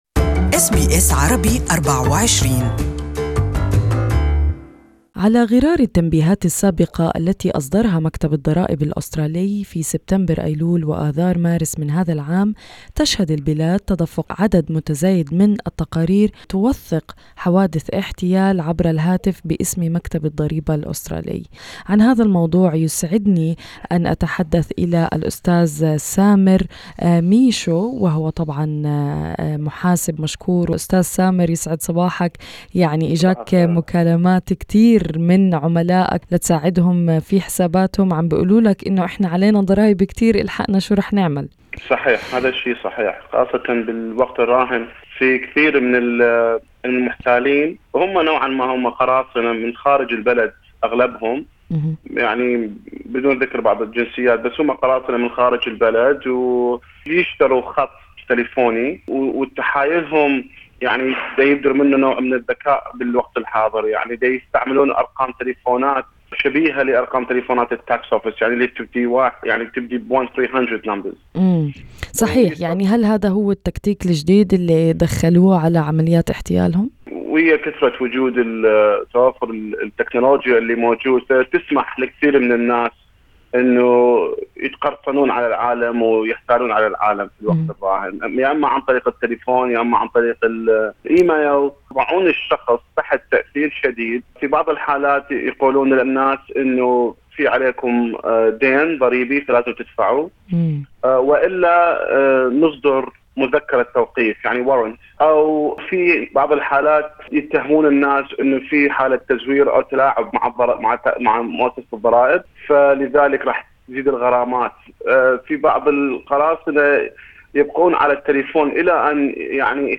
اللقاء الصوتي